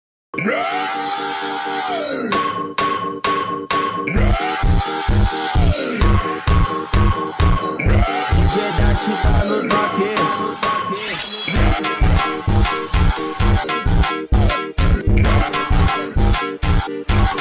The funk RAAAAAAAWWW sound button is from our meme soundboard library